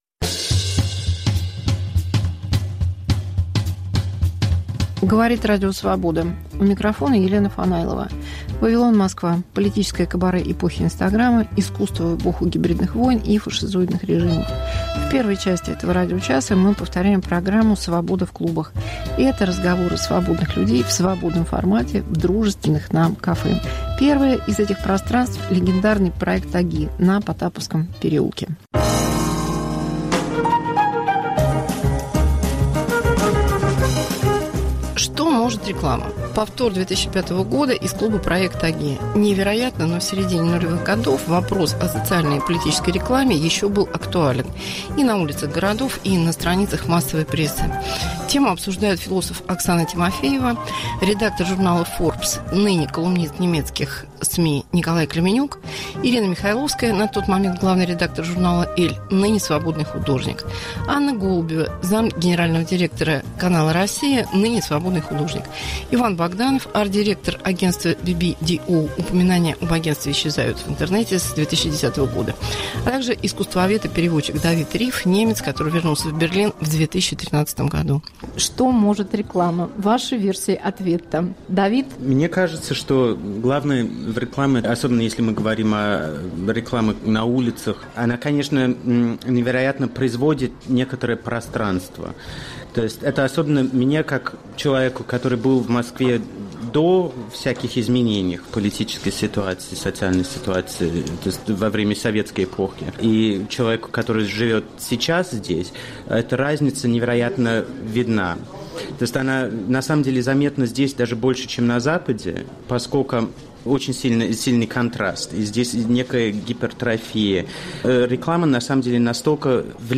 Вавилон Москва. Елена Фанайлова в политическом кабаре эпохи инстаграма. Мегаполис Москва как Радио Вавилон: современный звук, неожиданные сюжеты, разные голоса. 1.
Интервью с Маратом Гельманом, галеристом и арт-менеджером